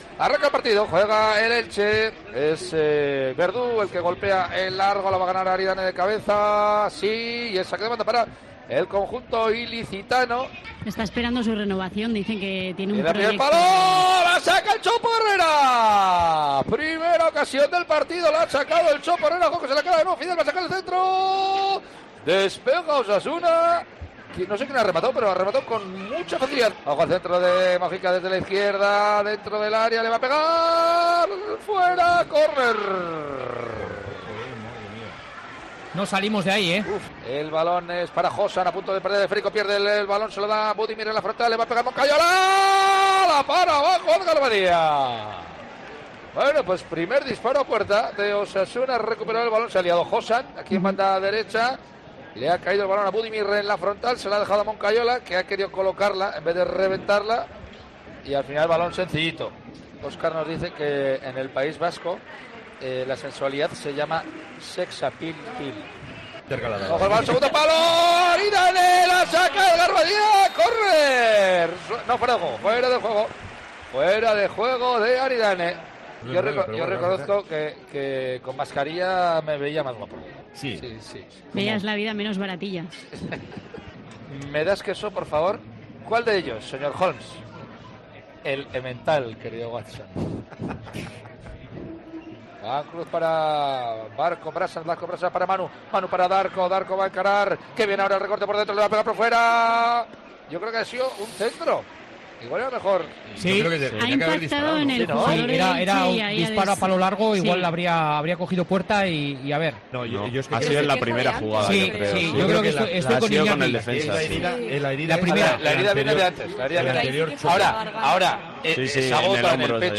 Resumen de la retransmisión del partido Elche 1-1 Osasuna en Osasuna a Ritmo de Rock & Roll - Tiempo de Juego Navarro del domingo 1 de mayo de 2022.